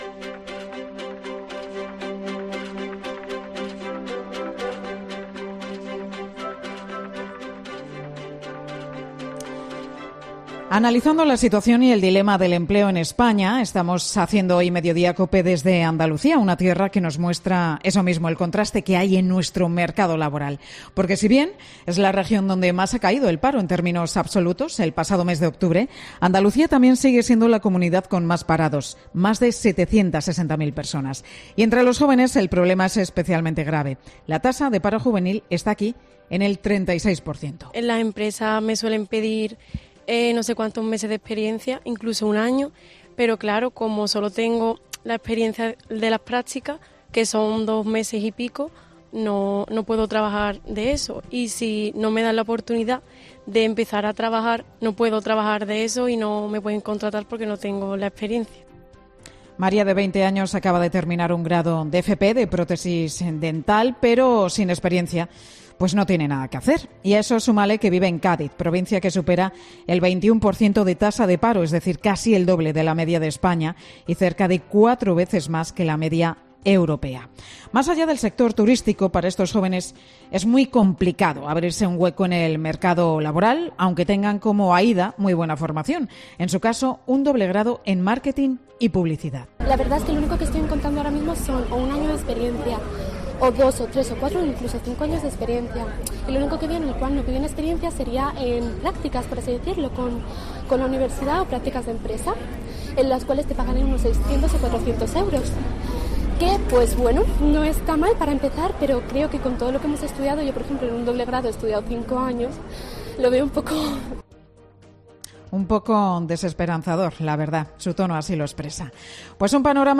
El equipo de 'Mediodía COPE' hace el programa desde Andalucía como muestra del dilema del empleo en España